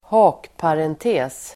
Ladda ner uttalet
Uttal: [²h'a:kparente:s]